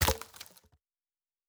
pgs/Assets/Audio/Fantasy Interface Sounds/Wood 01.wav at master
Wood 01.wav